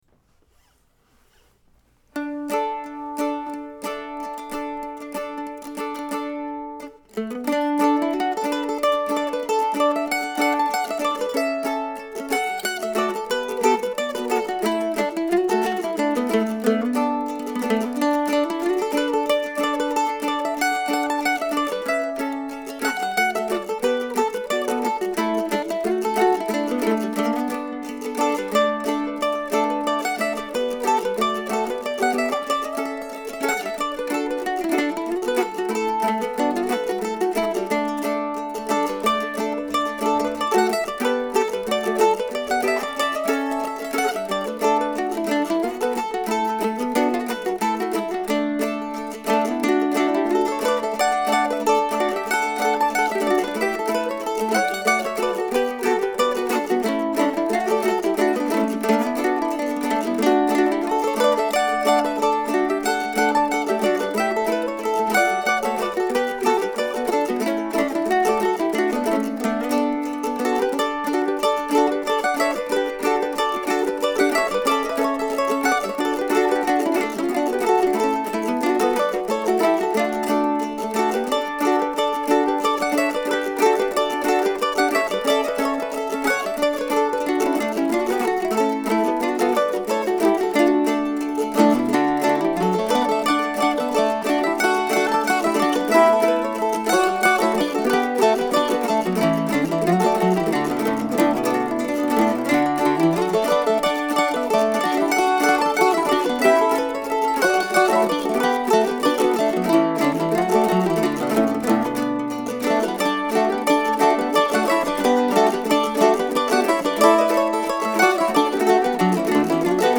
If you are in the Decorah area I will be playing solo mandolin at Java John's from 7 to 9 on Dec. 26 (Boxing Day).